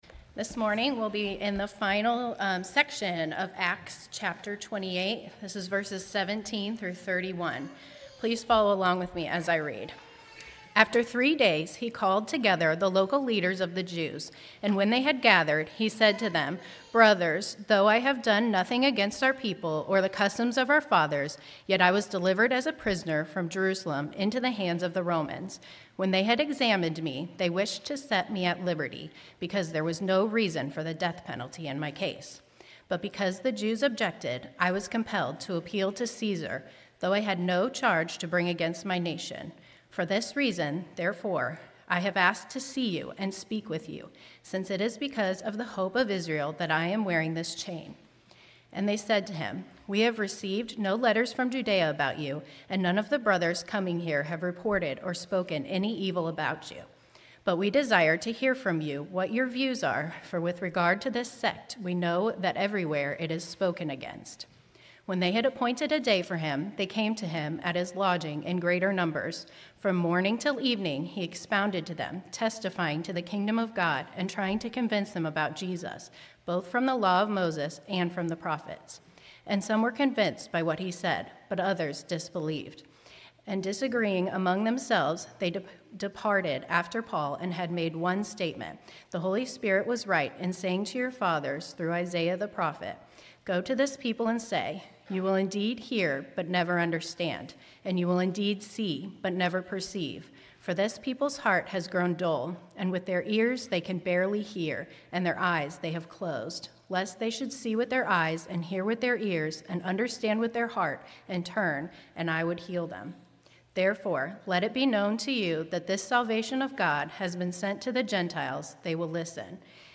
“Mission Unstoppable” – Acts 28:17-31 | Redeeming Grace Baptist Church